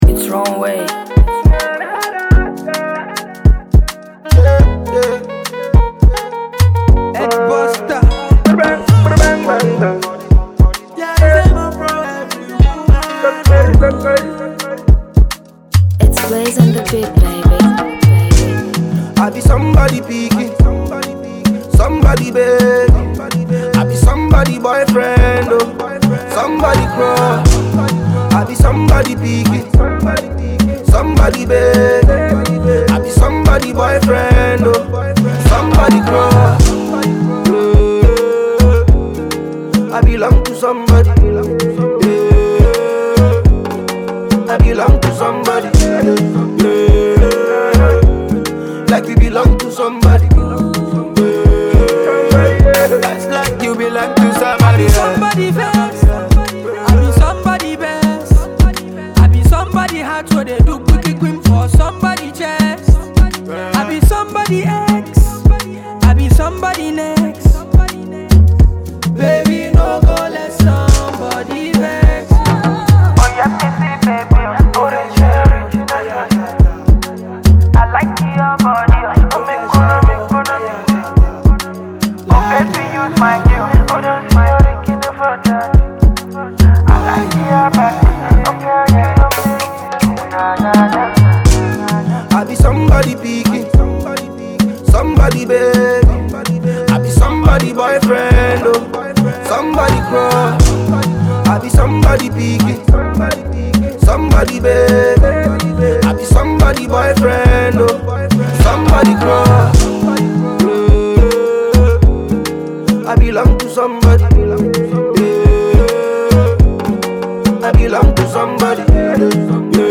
Afro-pop
Nigerian Afro-pop musician and rapper